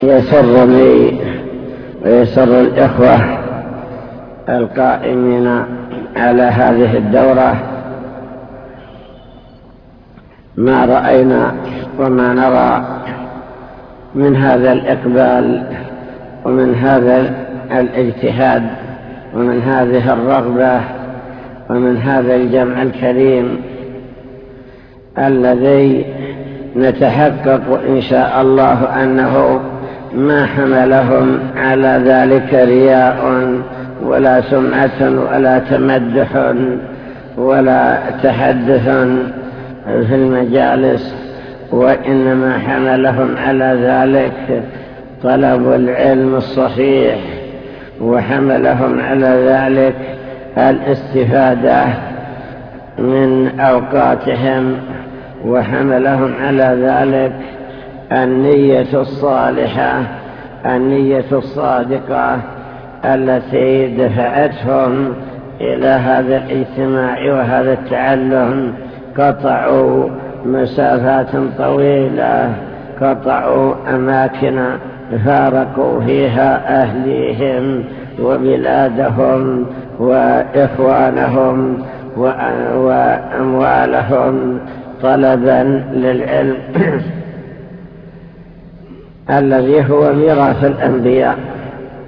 المكتبة الصوتية  تسجيلات - لقاءات  لقاء مفتوح مع الشيخ